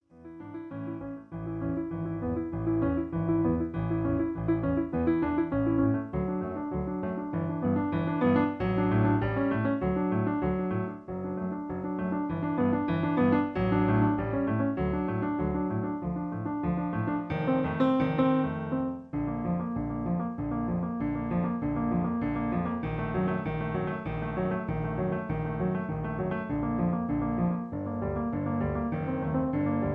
Original key (D) Piano Accompaniment